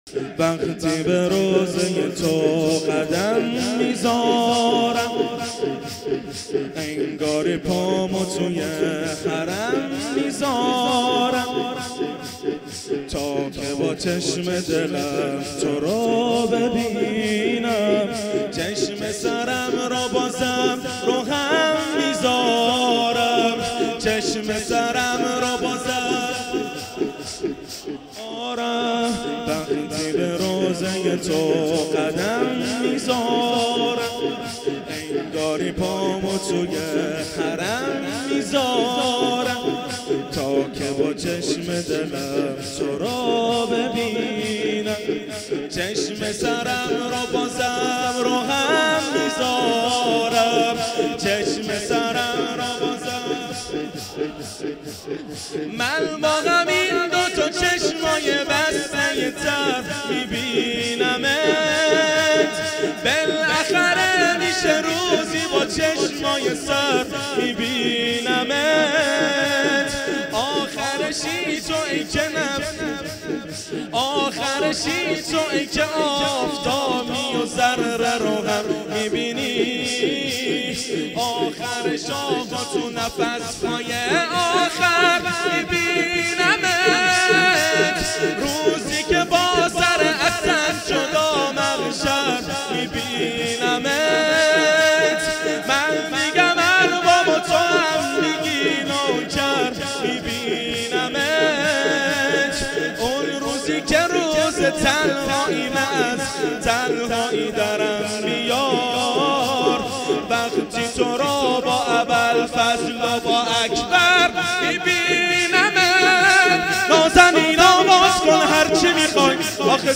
شور | وقتی به روضه تو قدم میذارم، انگاری پامو توی حرم میذارم
مداحی
ماه رمضان 1438 هجری قمری | هیأت علی اکبر بحرین